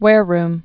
(wârrm, -rm)